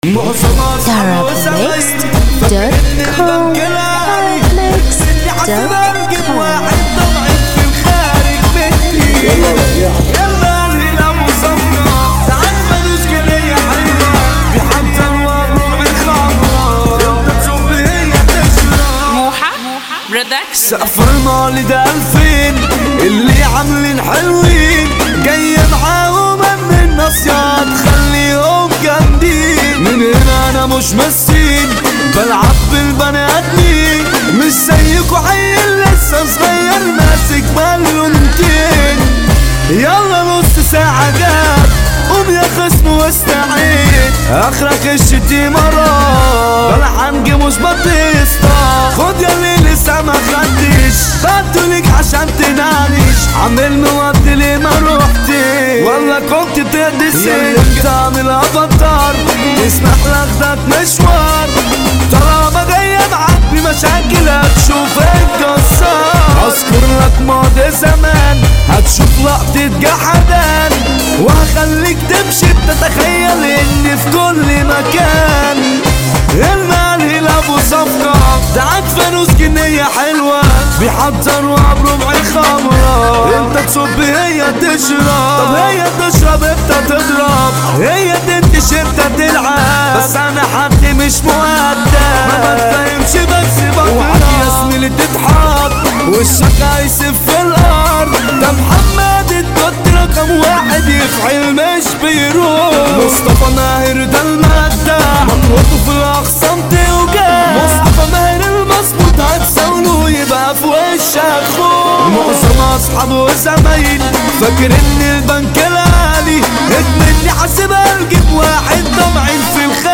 • النوع : festival